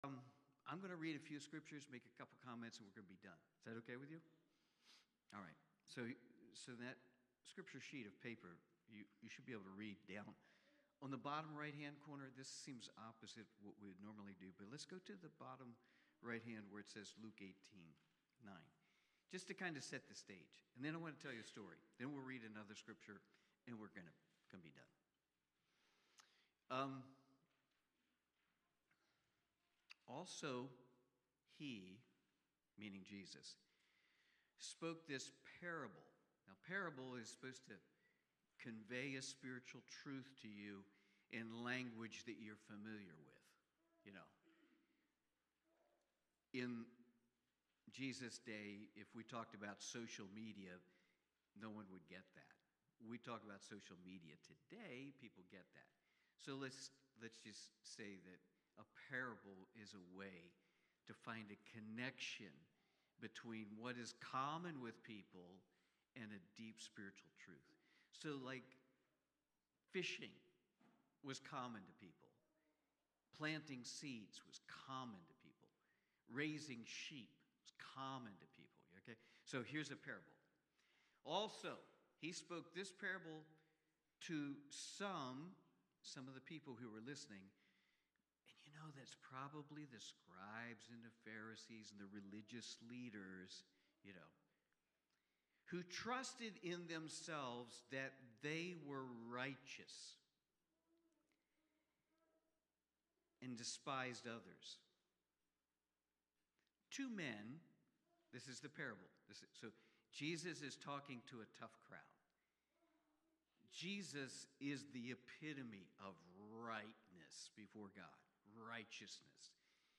1 Corinthians Watch Listen Save Cornerstone Fellowship Sunday morning service, livestreamed from Wormleysburg, PA.